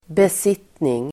Uttal: [bes'it:ning]